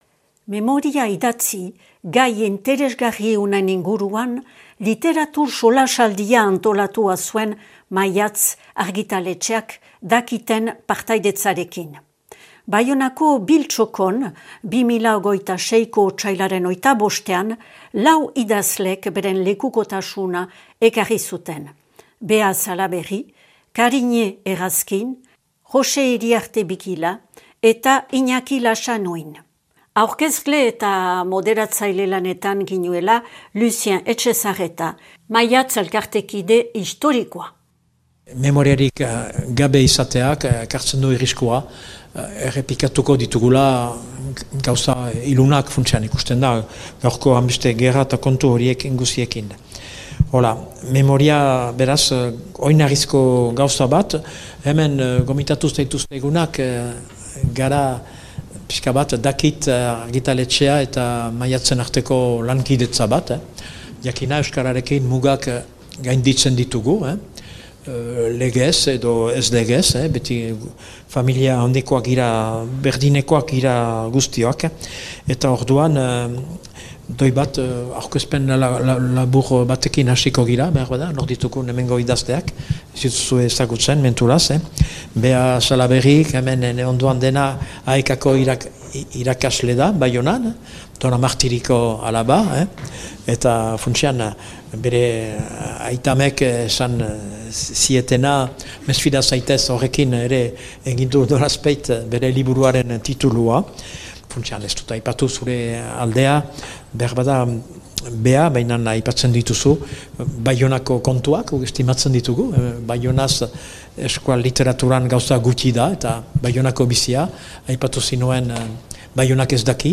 Literatur solasaldia : memoria idatzi (I. partea)